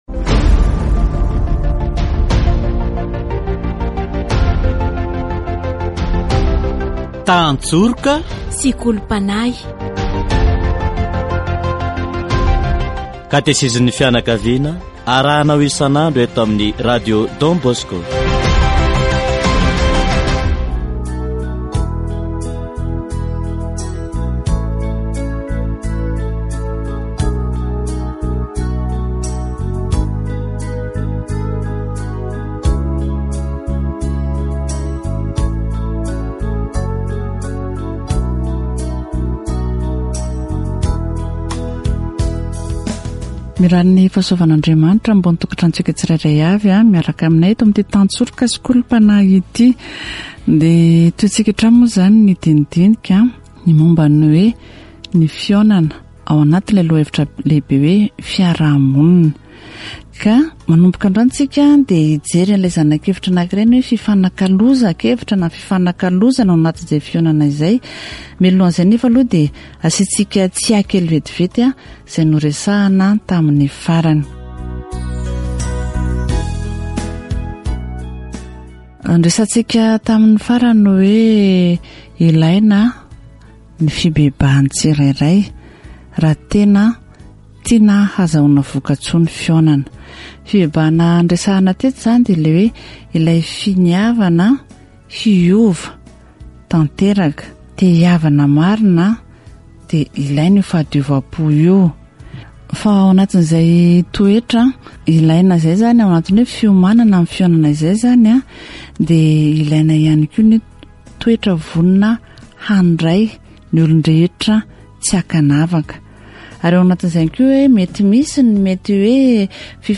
Category: Deepening faith
The discussion is not a "negotiation" but an opportunity to express what is persuasive for oneself, with respect for others. Catechesis on the meeting, the exchange of ideas